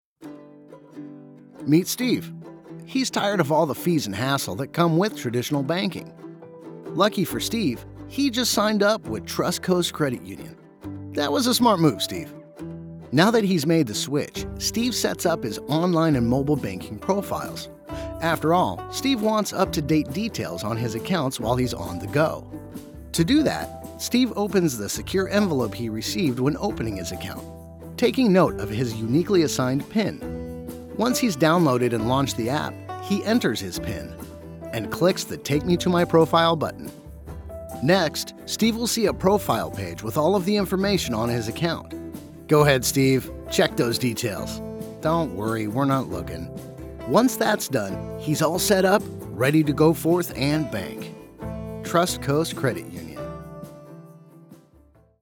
Explainer Sample
Middle Aged
I have a broadcast quality home studio and love connecting for directed sessions.